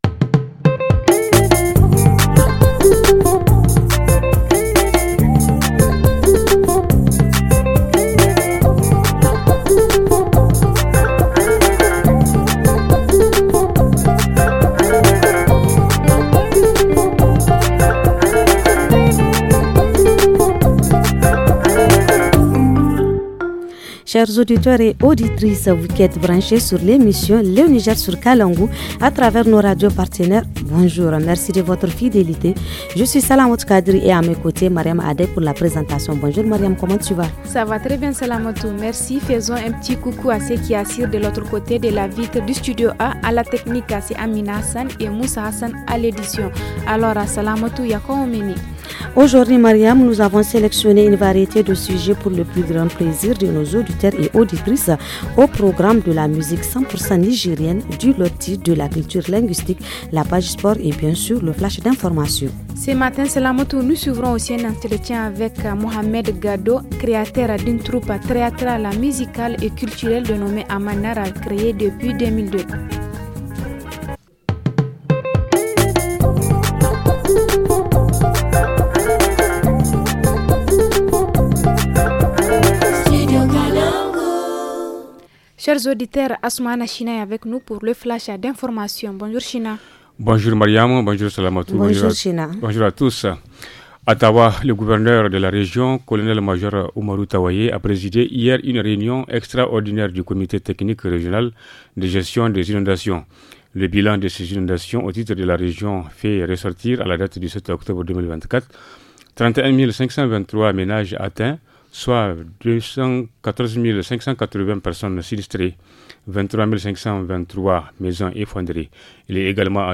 La rubrique hebdomadaire nous parle de la crise d’épilepsie chez les enfants. En reportage région, nous allons parler de la fête de ‘’tendé’’ à Agadez.